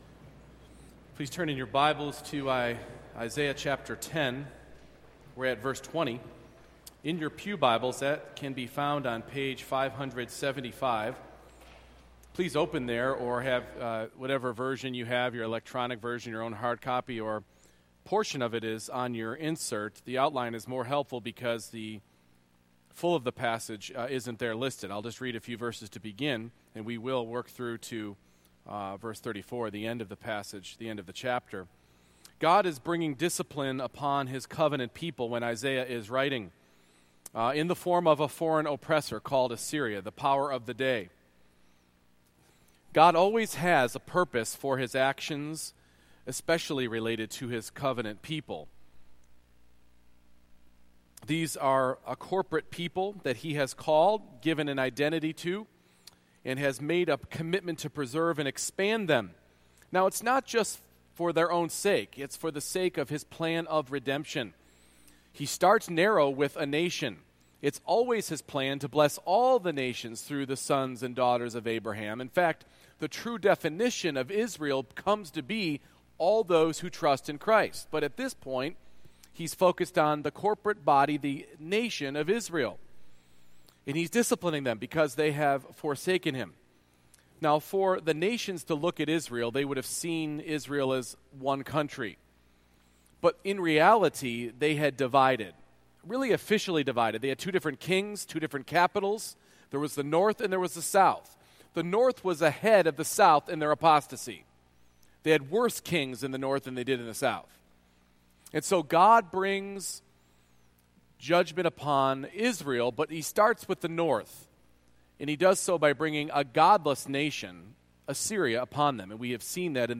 Isaiah 10:20-34 Service Type: Morning Worship God graciously sends hardship to purify the church and sanctify the saints.